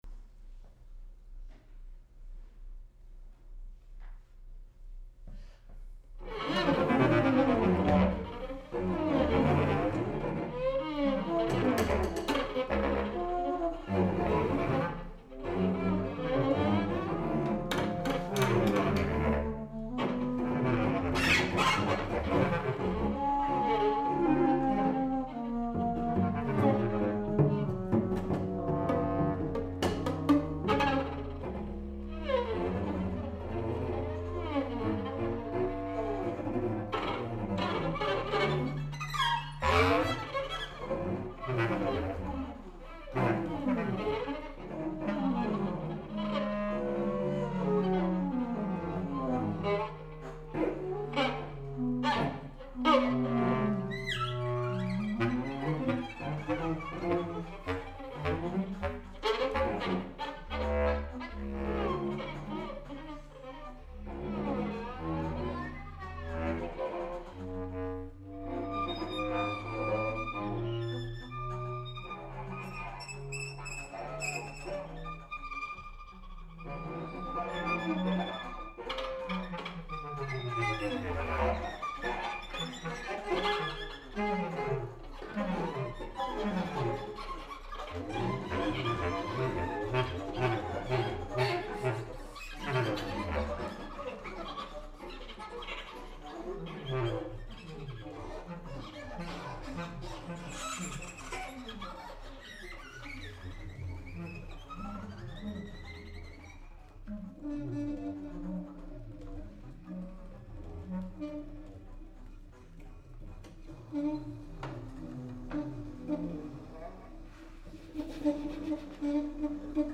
commisioned by Seattle Chamber Players
bass, C, piccolo flutes, bass clarinet, violin, cello